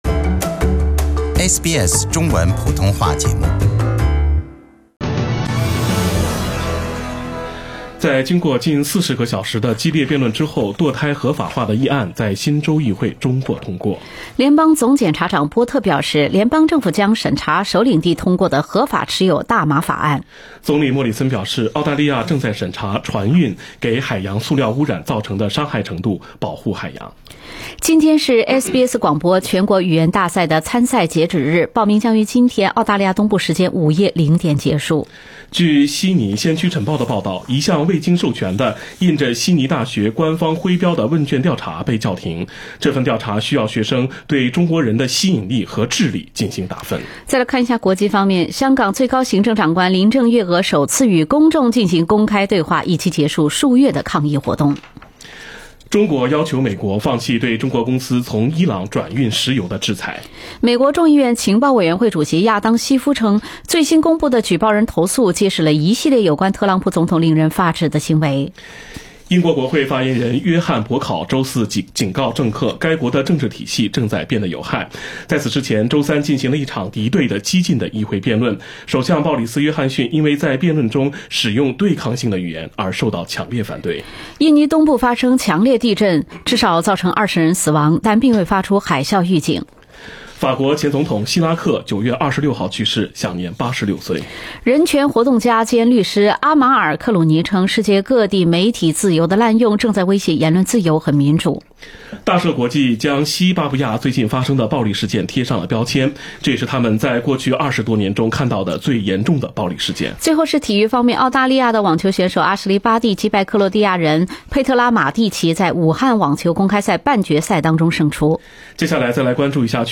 SBS早新闻（9月27日）